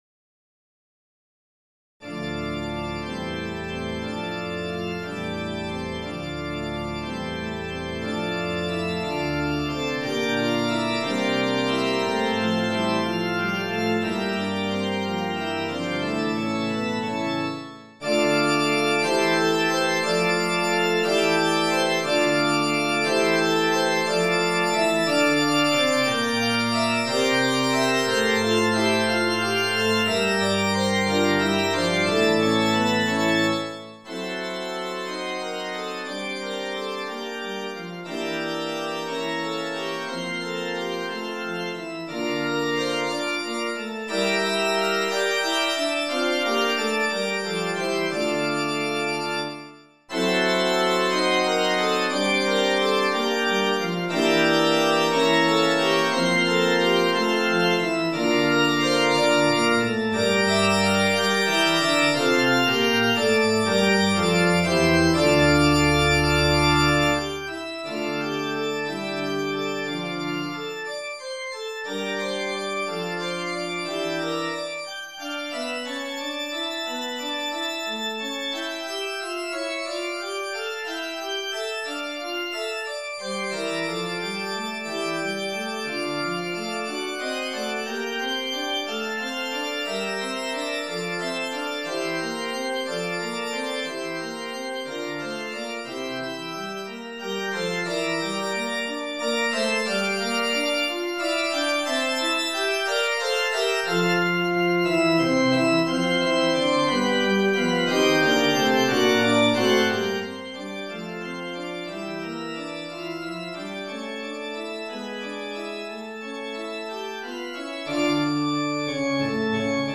Henri Nibelle, Offertoire pour orgue ou harmonium, dédicacé "à Monsieur Gabriel Meunier"
Offertoire en ré mineur dans Les Maîtres Contemporains de l'Orgue, vol. 1 (1912)